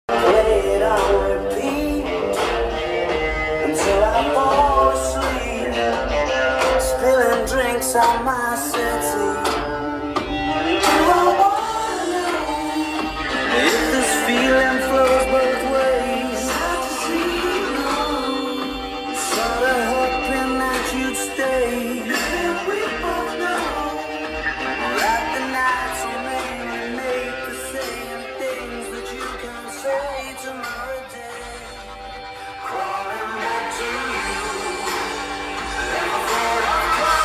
very clean and loud